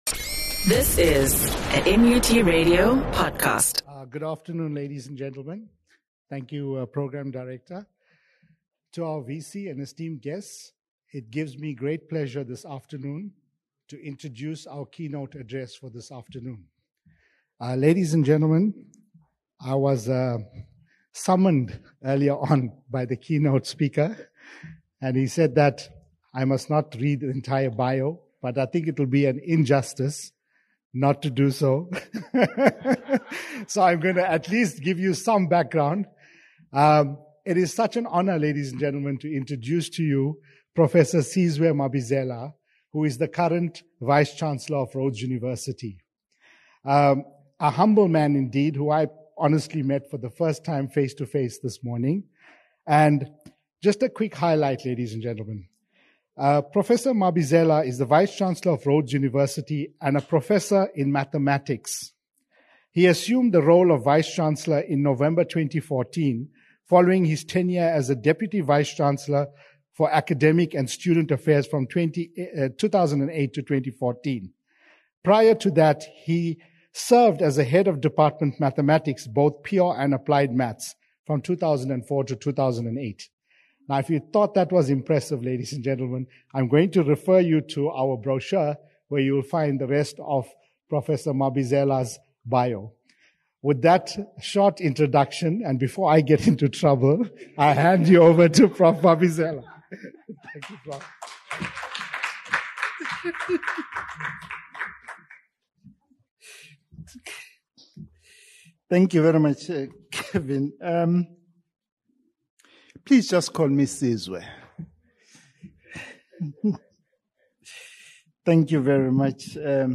18 Nov MUT CEAD hosted a Scholarship of Engagement Conference
keynote address